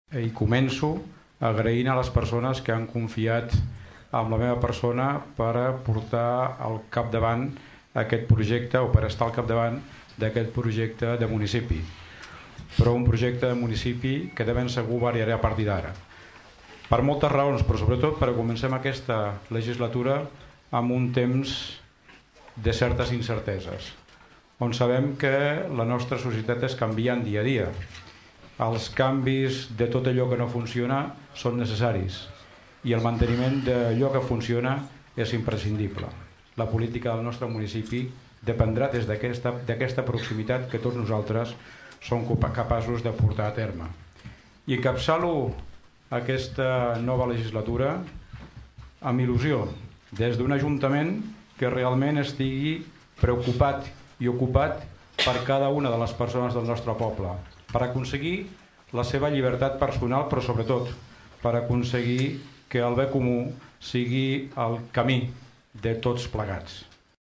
L’auditori del Teatre Clavé s’ha omplert de gom a gom durant en Ple de constitució del nou ajuntament.